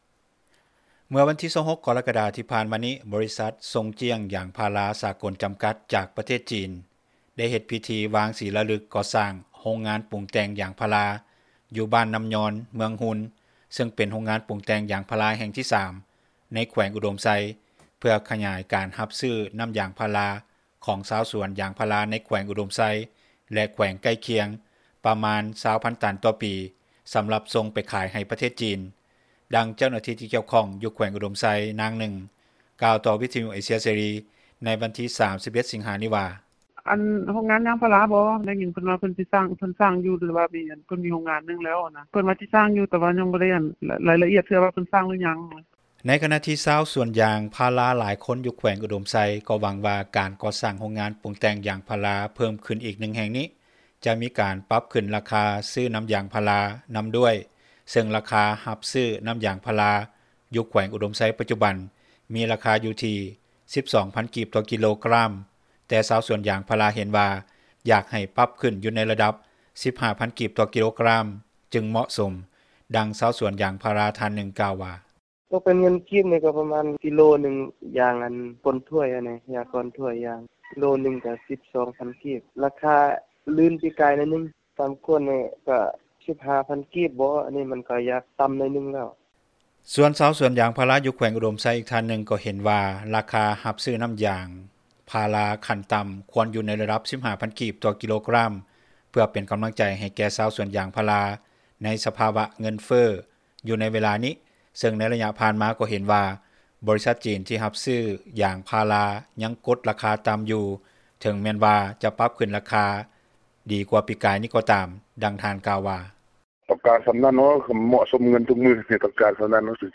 ດັ່ງຊາວສວນຢາງພາຣາ ທ່ານນຶ່ງກ່າວວ່າ:
ດັ່ງຊາວສວນຢາງພາຣາ ອີກທ່ານນຶ່ງກ່າວວ່າ: